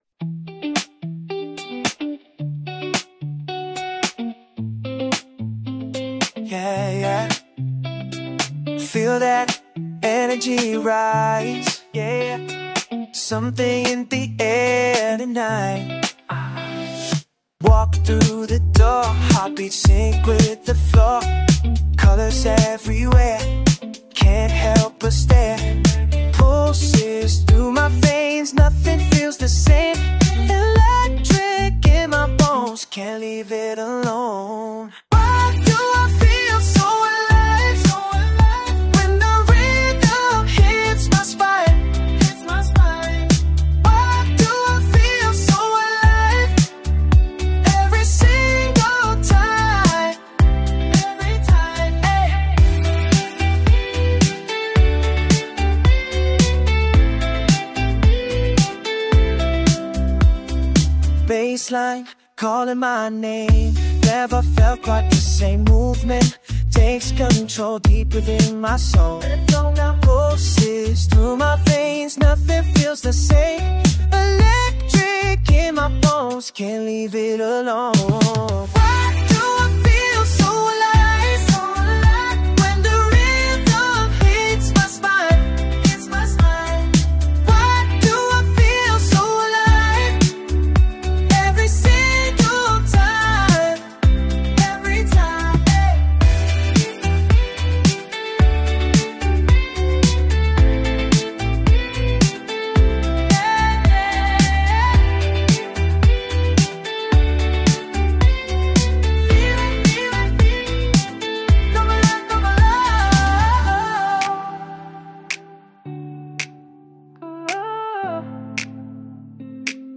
Chill Vibes